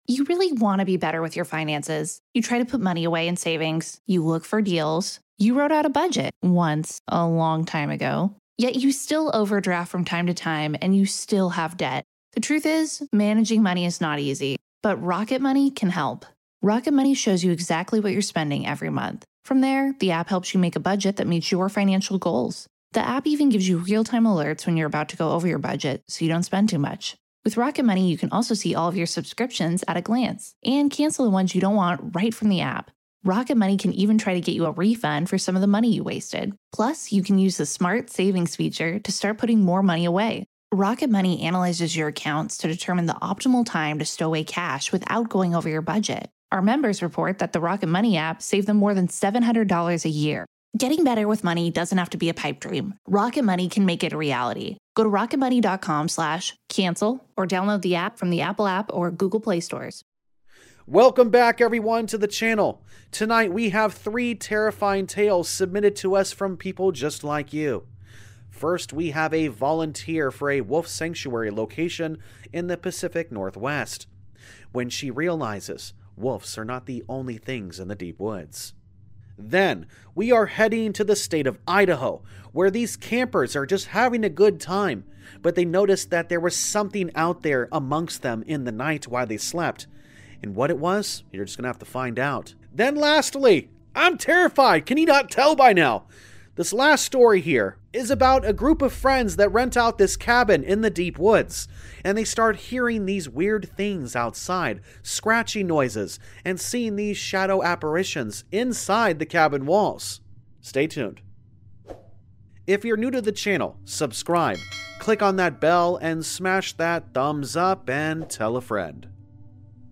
All Stories are read with full permission from the authors